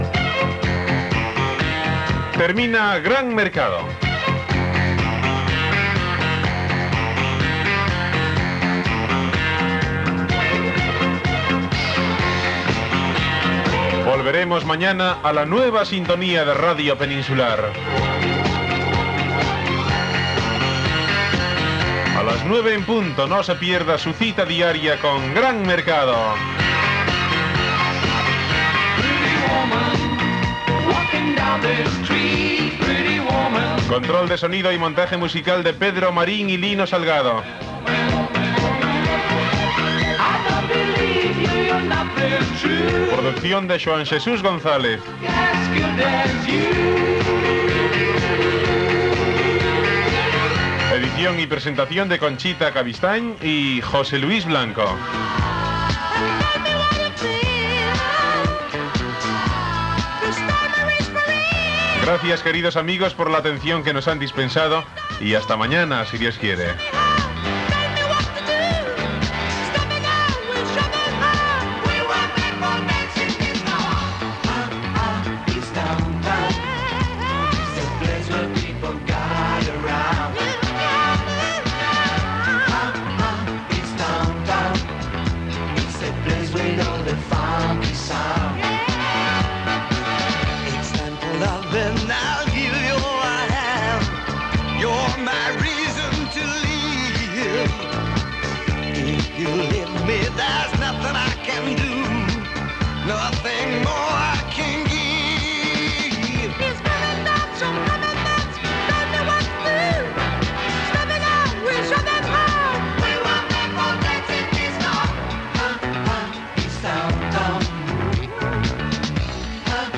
Final del programa, publicitat i article de la Constitució Espanyola.
Entreteniment